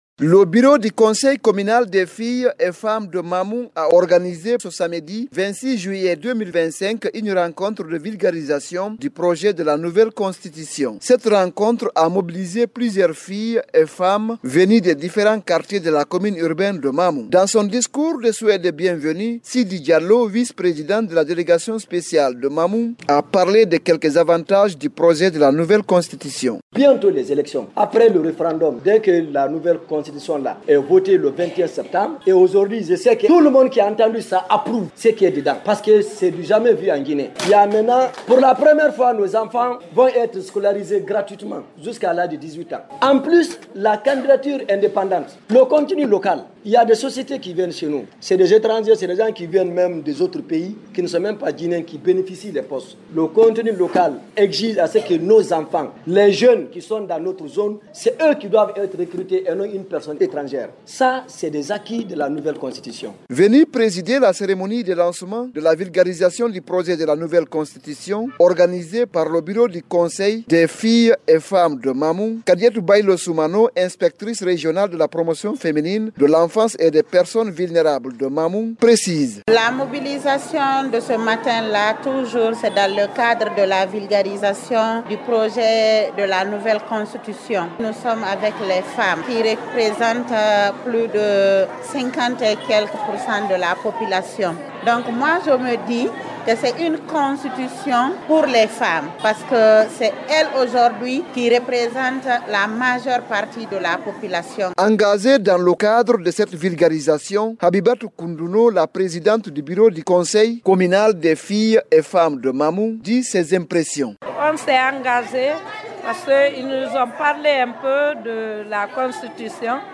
C’est la salle de réunion de la commune de Mamou qui a servi de cadre à la rencontre.